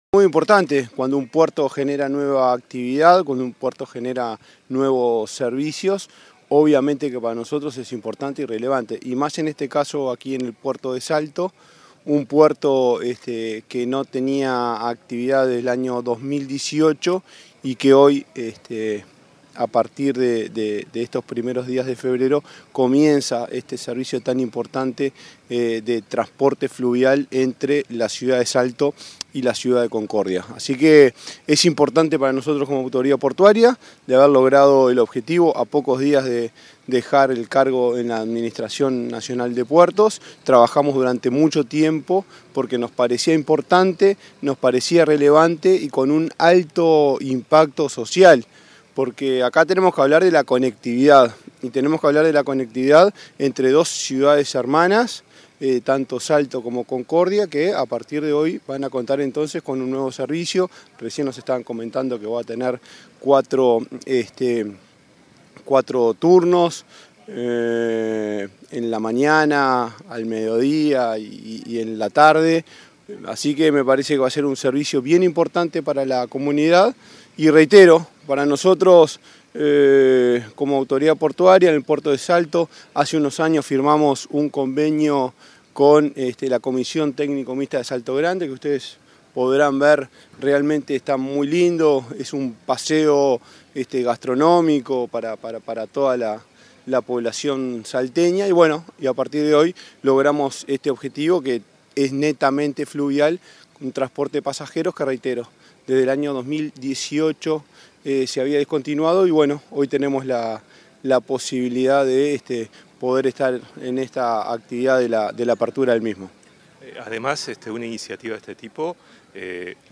Entrevista al director de la ANP, Juan Curbelo